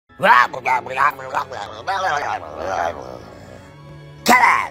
Goofy Ahh Noises Meme